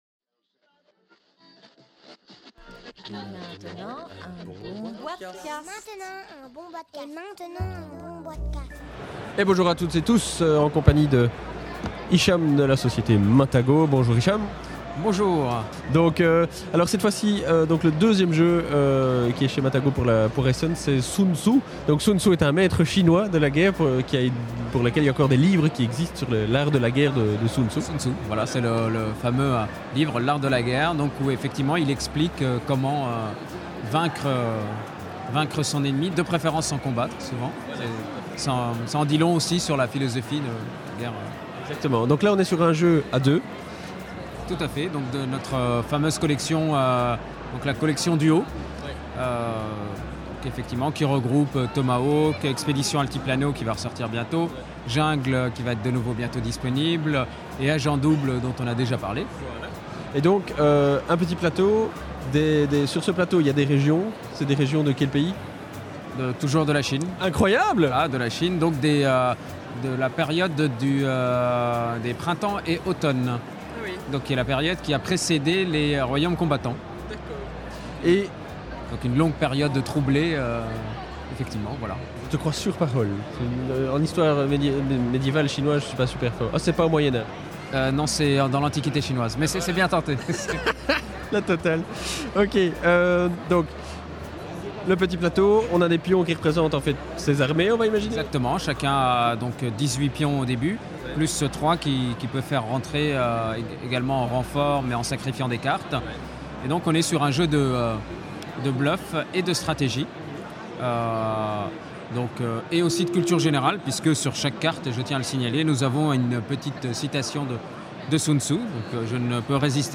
(enregistré au Salon international du Jeu de Société de Essen – Octobre 2010)